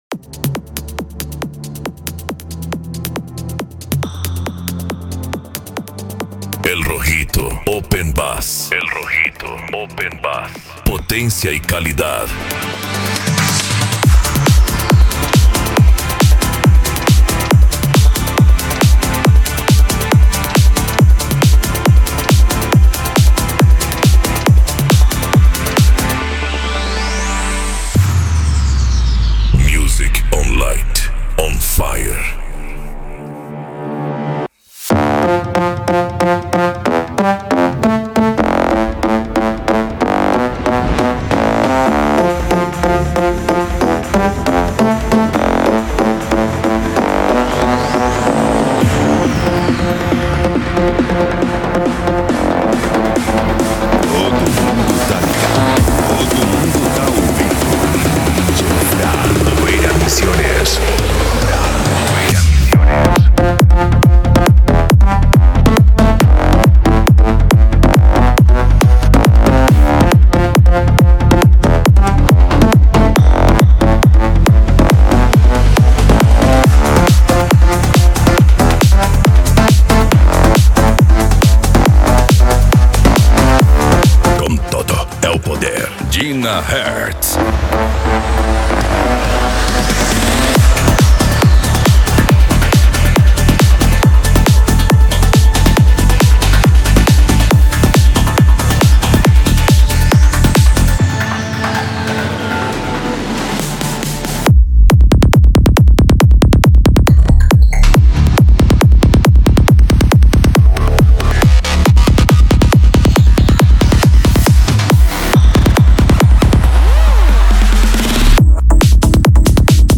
Electro House
Psy Trance
Remix